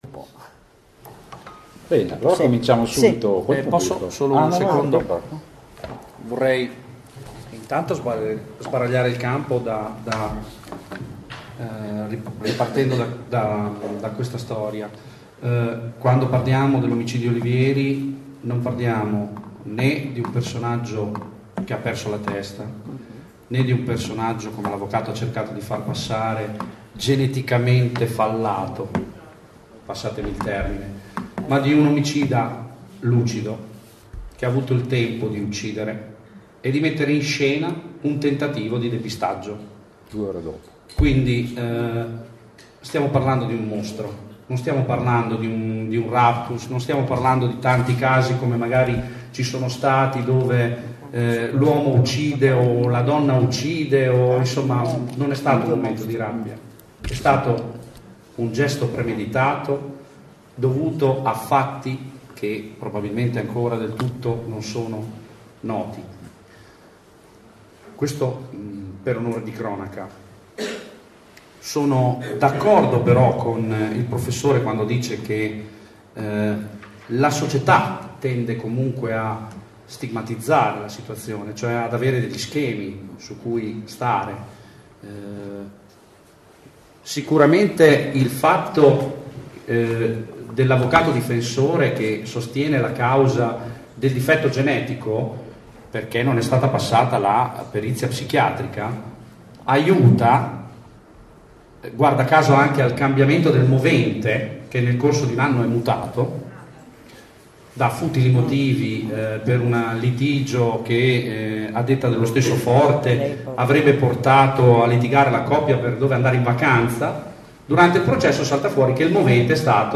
Durante la discussione, che ha visto anche la partecipazione attiva del pubblico, si è cercato di affrontare il fenomeno violenza sulle donne non solo riferito al rapporto di genere uomo-donna ma a un più ampio aspetto.